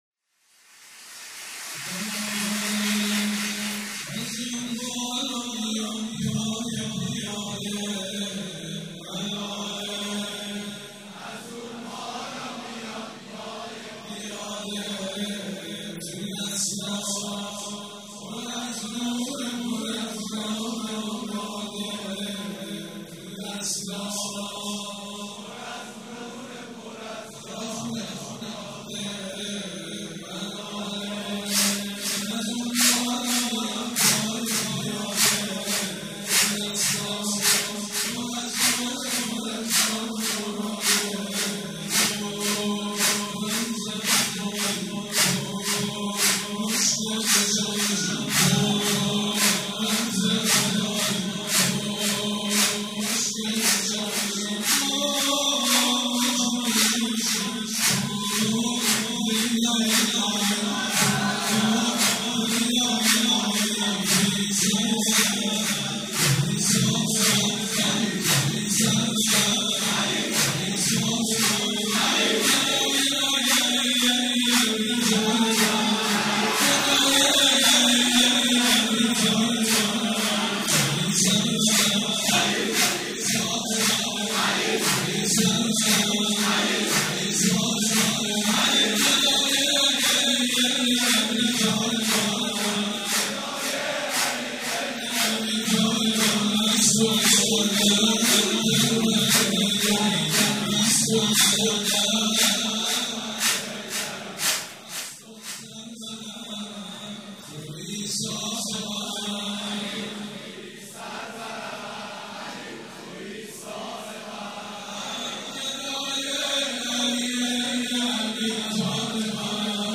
مناسبت : ولادت امیرالمومنین حضرت علی علیه‌السلام
مداح : سیدمجید بنی‌فاطمه قالب : سرود